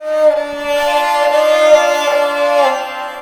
SARANGHI1 -L.wav